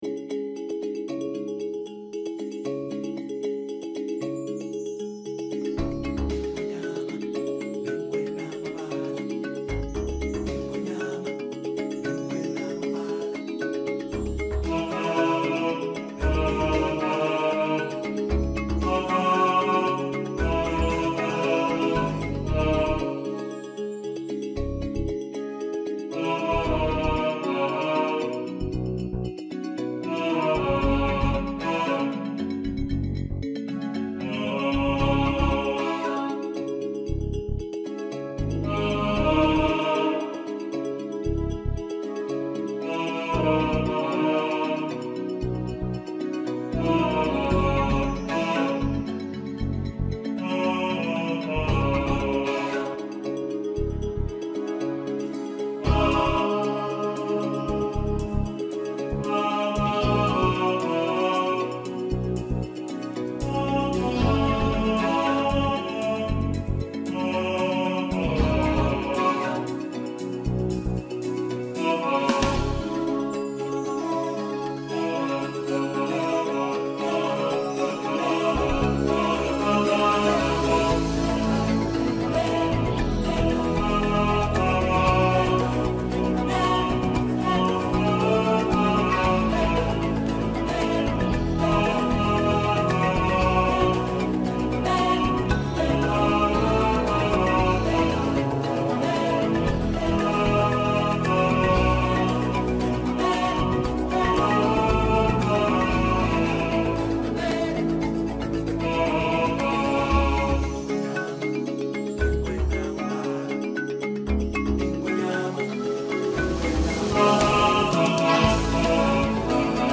He Lives In You – All Voices | Ipswich Hospital Community Choir